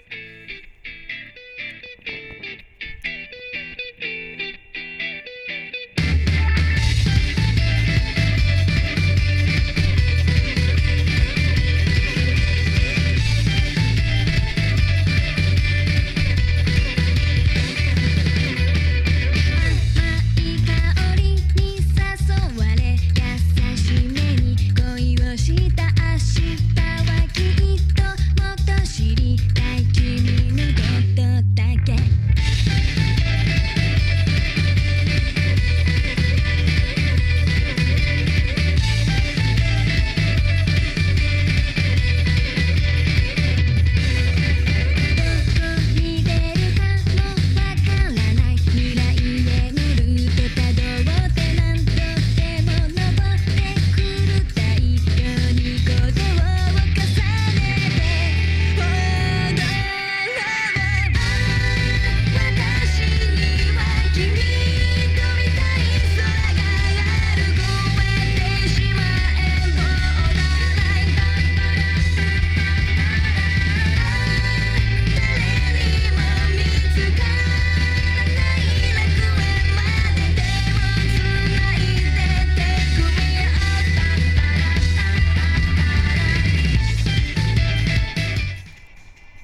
・ステレオICレコーダー：オリンパス LS-20M リニアPCM 44.1kHz/16bit 非圧縮WAV形式
・Apple純正イヤフォン：アナログ端子